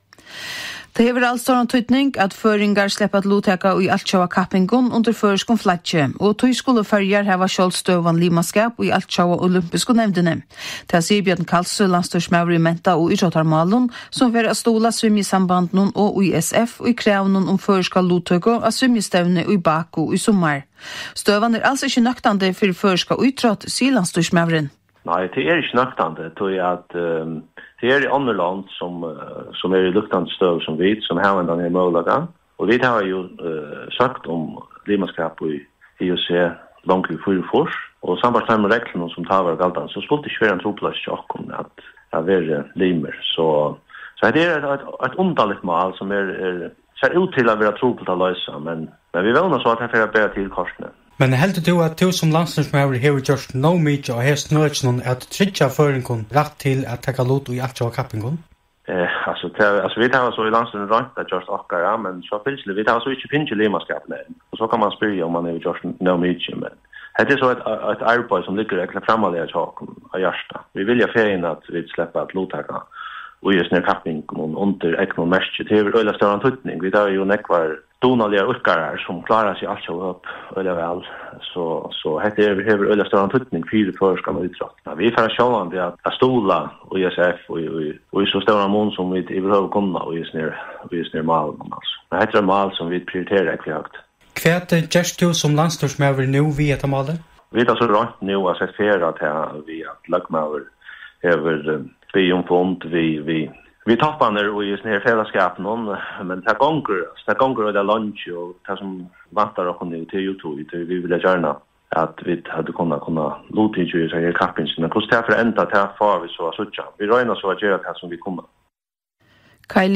Brot úr útvarpstíðindunum hjá Kringvarpi Føroya, týsdagin hin 3. mars 2015 kl 15:00, um støðuna nú Føroyar ikki sleppa at luttaka á Evropeisku JuniorMeistarastevnuni í svimjing 2015.